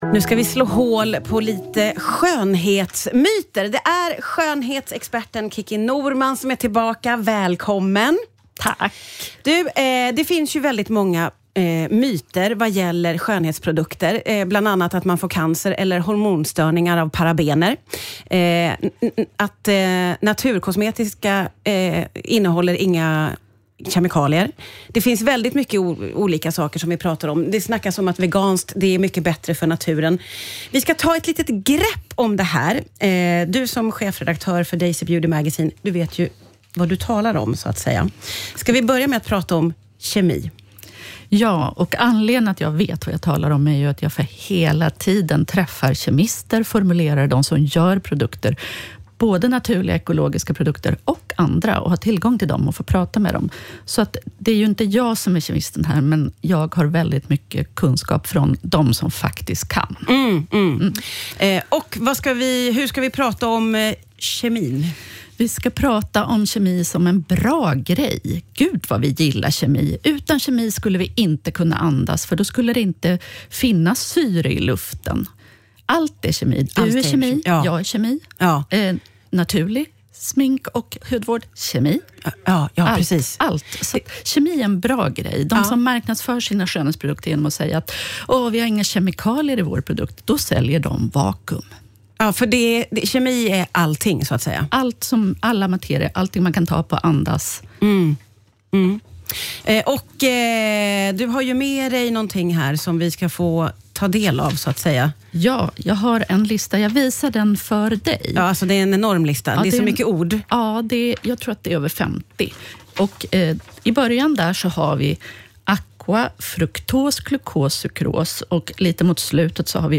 gästar studion och slår hål på flera skönhetsmyter. Hon berättar om myten att parabener är farligt, att naturkosmetika inte innehåller några kemikalier och att det skulle vara bättre för allergiker.